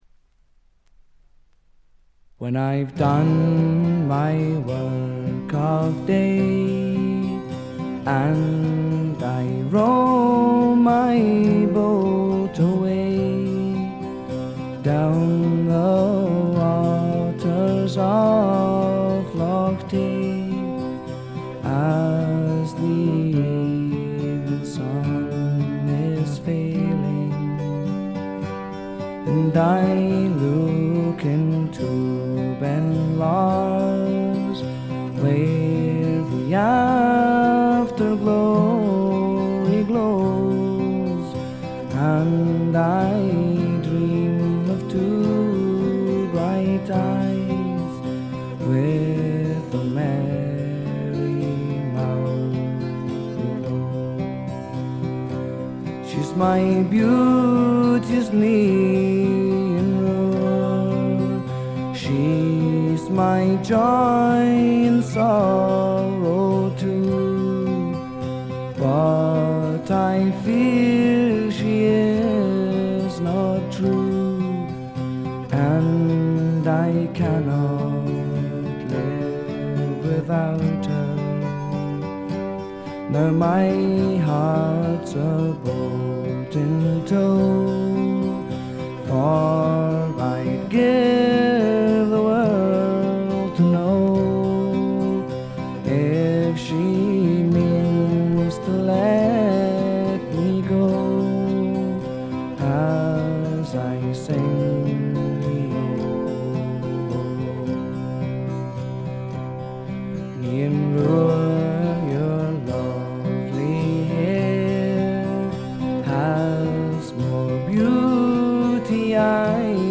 ほとんどノイズ感無し。
試聴曲は最大の魅力である哀愁味あふれる沁みる歌声に焦点を当てましたが、この人は楽器の腕前も一級品です。
試聴曲は現品からの取り込み音源です。